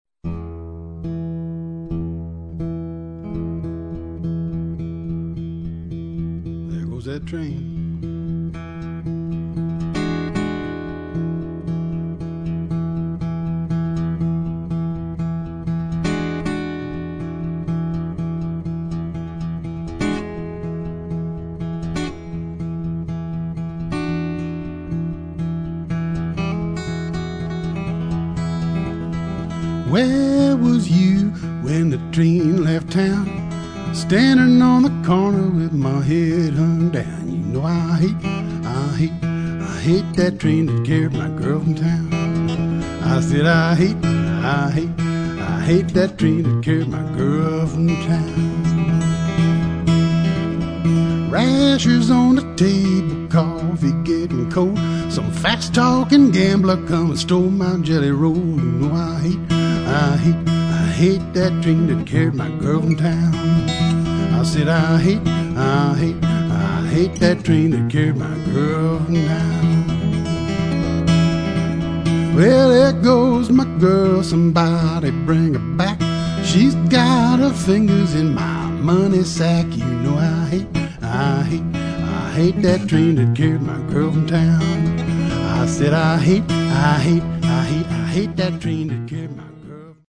for an album of blues & ragtime.
of a syncopated guitar played with a strong right thumb
measuring time with a steady thump.